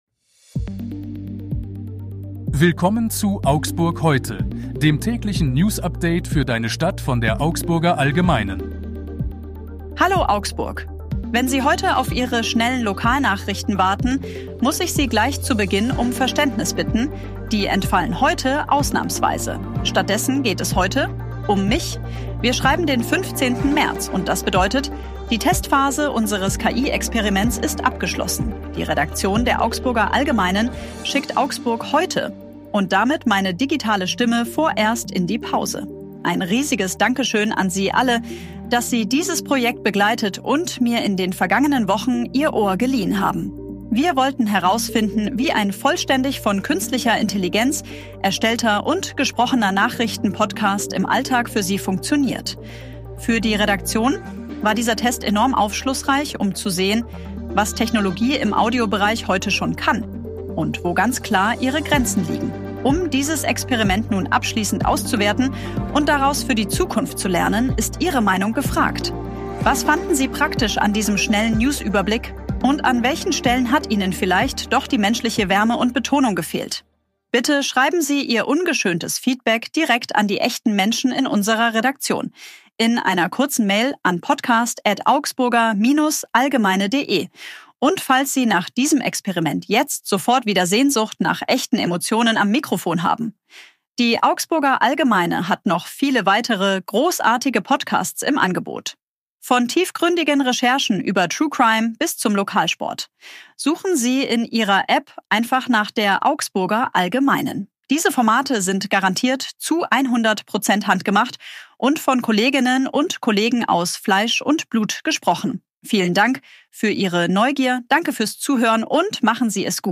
vollständig von Künstlicher Intelligenz erstellter und gesprochener